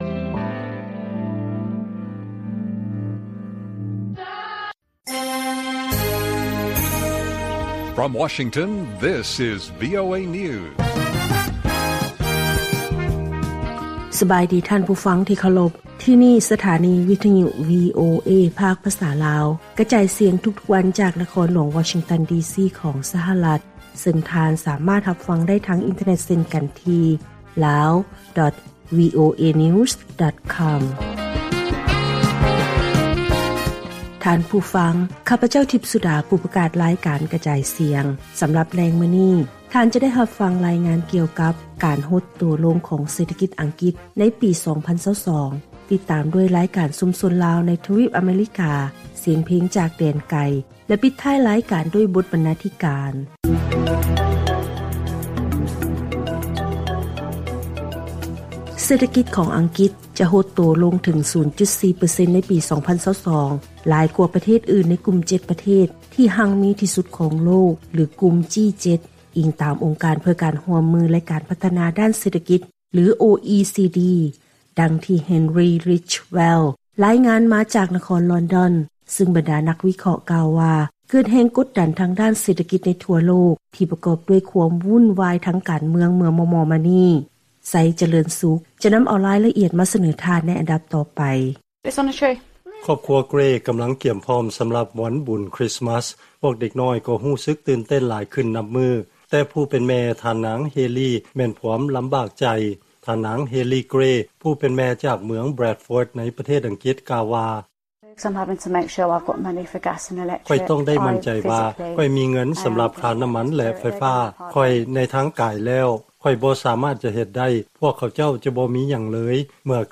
ວີໂອເອພາກພາສາລາວ ກະຈາຍສຽງທຸກໆວັນ ສຳລັບແລງມື້ນີ້ ເຮົາມີ: 1.